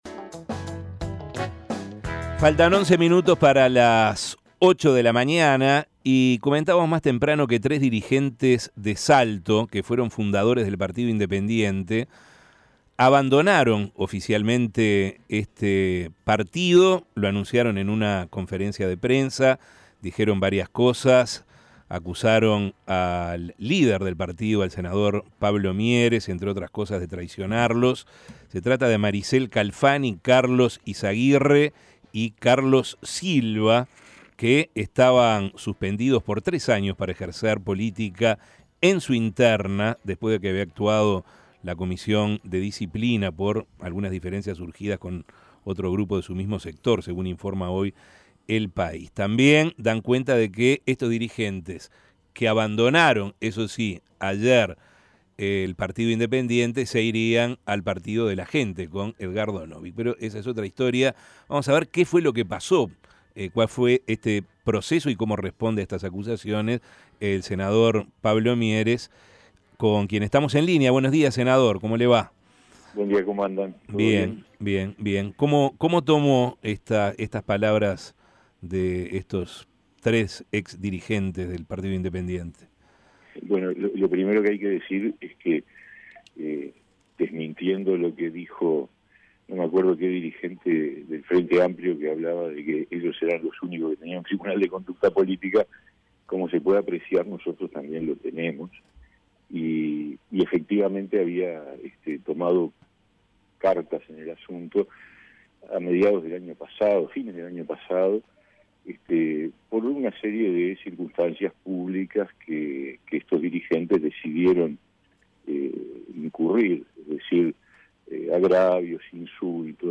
Pablo Mieres dijo a La Mañana de El Espectador, que de parte de estos dirigentes se recibieron durante mucho tiempo "insultos y agravios" de "forma pública".